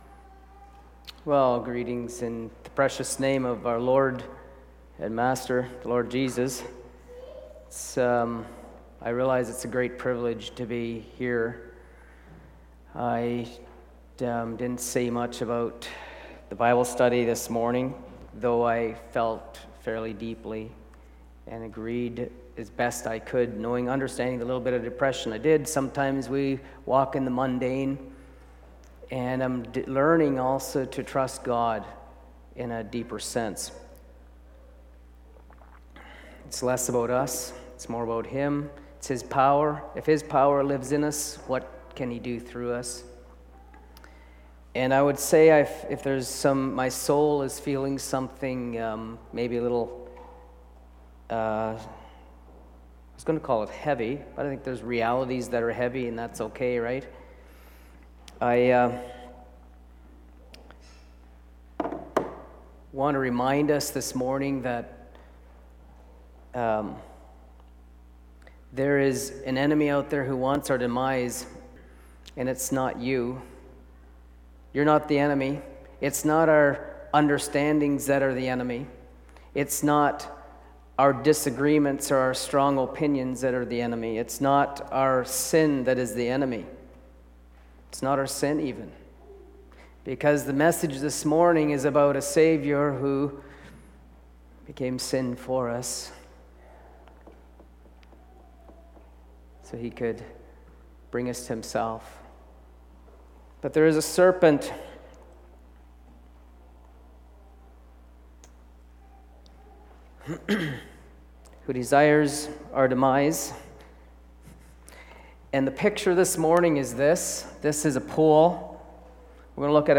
Series: Bible Teaching
Bible Teaching Service Type: Sunday Morning Preacher